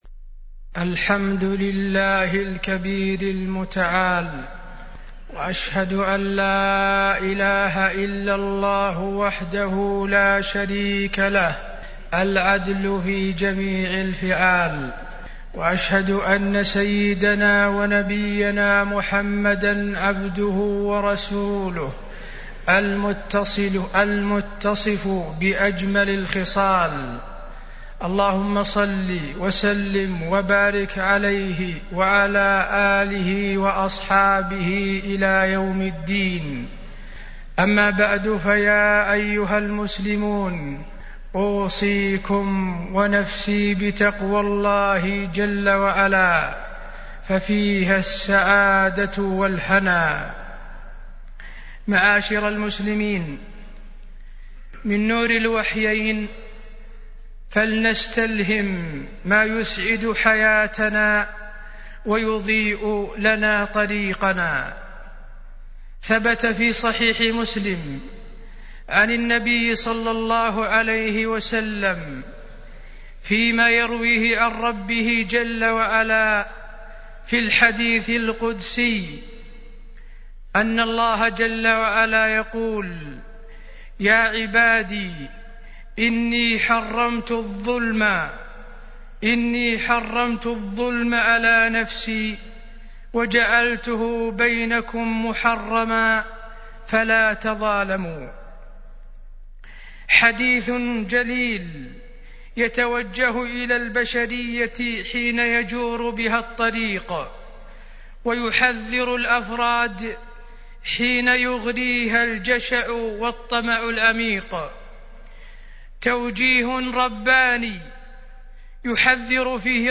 تاريخ النشر ٢٤ رجب ١٤٢٧ هـ المكان: المسجد النبوي الشيخ: فضيلة الشيخ د. حسين بن عبدالعزيز آل الشيخ فضيلة الشيخ د. حسين بن عبدالعزيز آل الشيخ عاقبة الظلم